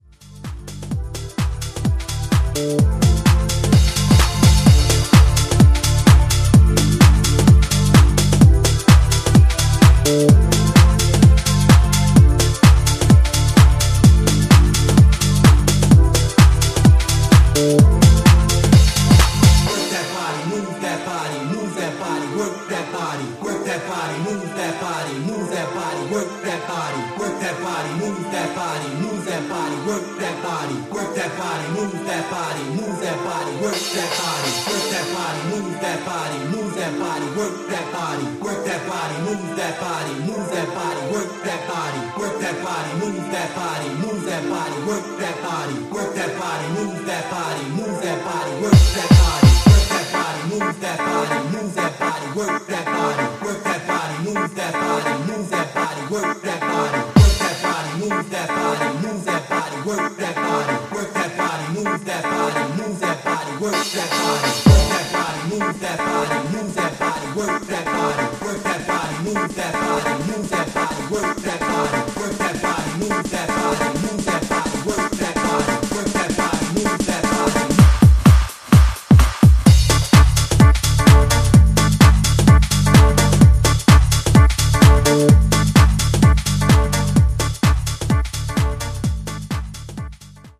ここでは、モダンでバウンシーなプログレッシヴ・ハウス路線の4曲を展開。